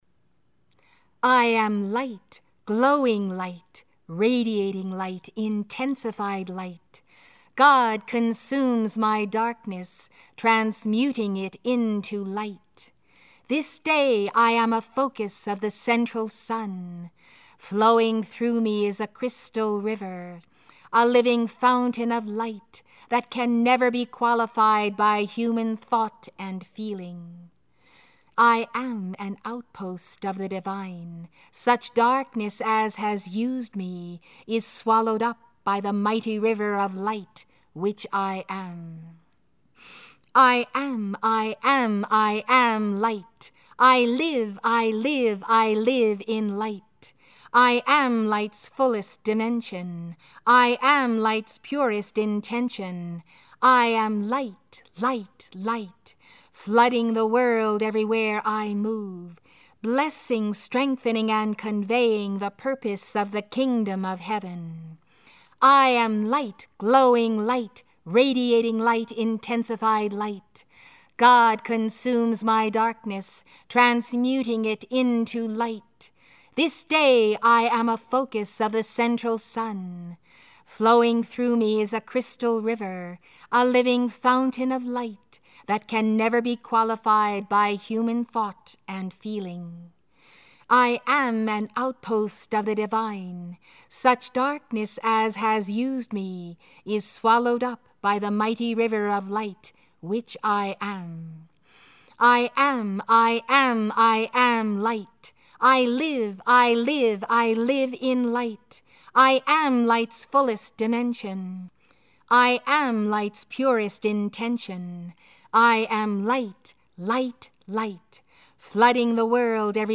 I AM Light – Decree
I_AM_Light_decree.mp3